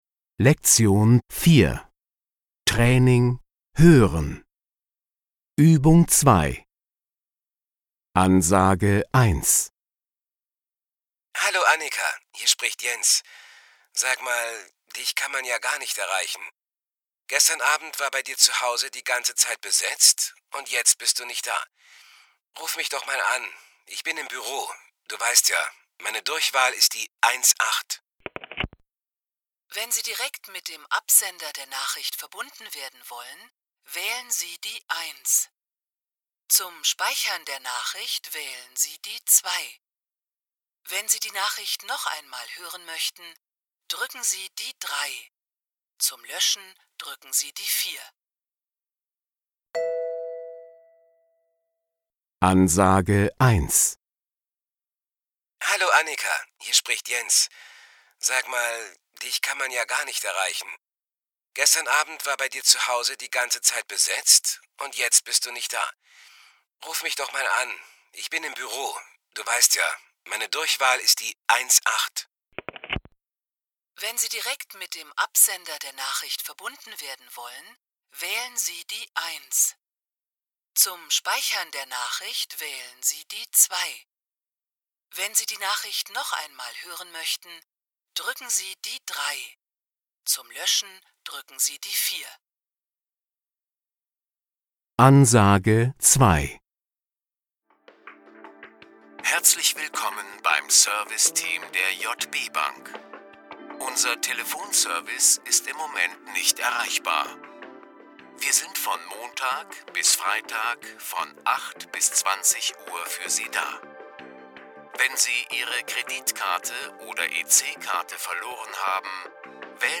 Hearing - Free miscellaneous icons Training Kurzdurchsagen – Teil 2
Sie hören jede Aufgabe zweimal.